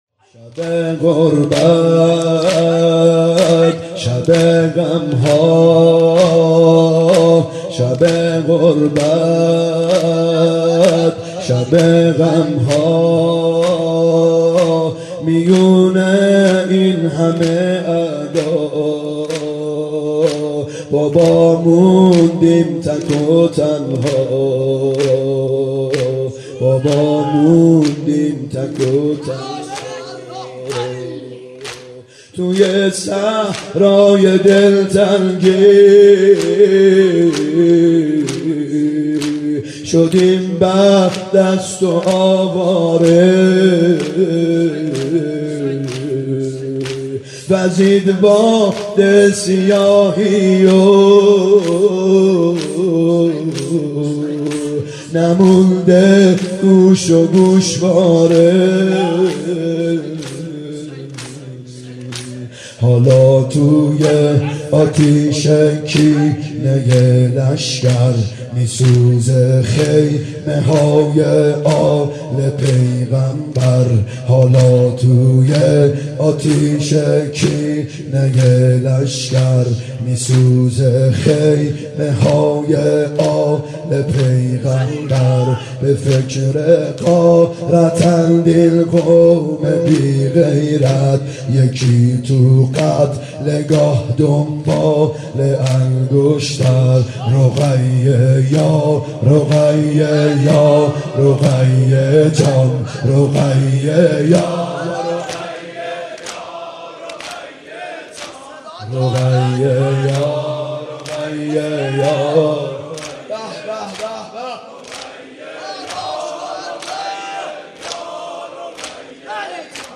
محرم 90 شب سوم زمینه (شب غربت شب غم ها
محرم 90 ( هیأت یامهدی عج)